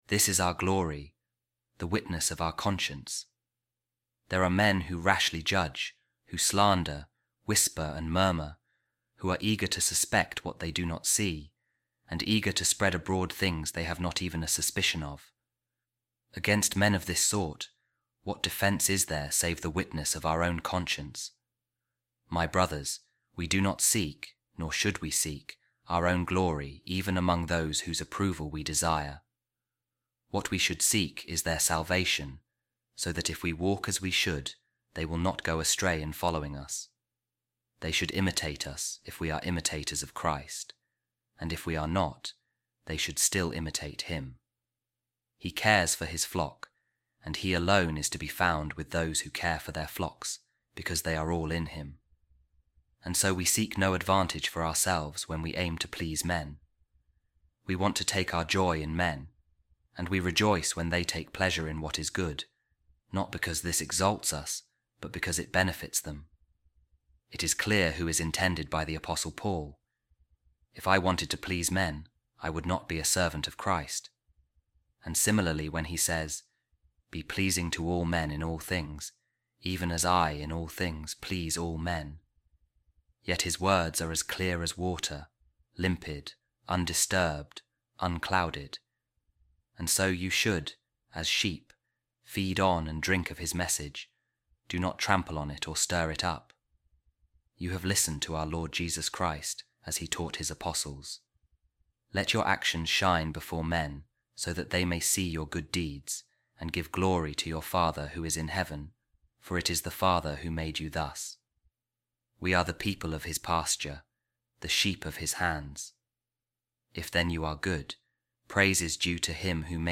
A Reading From The Sermons Of Saint Augustine | Servant Of Jesus Christ